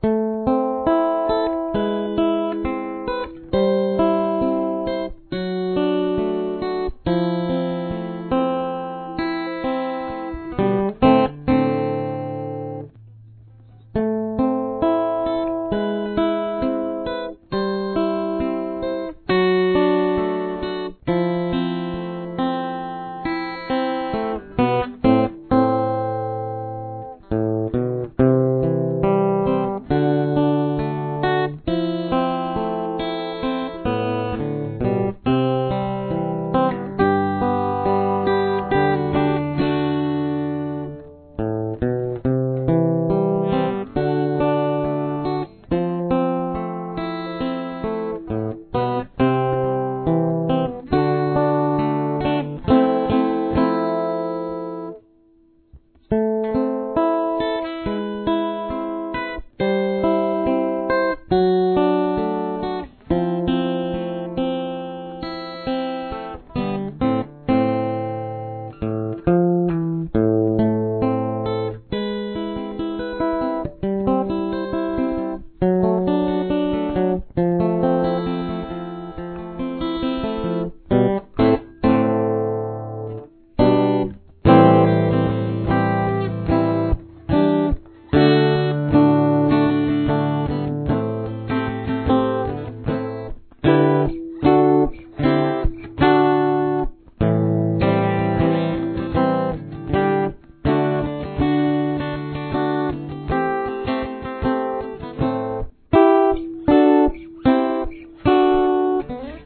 Verse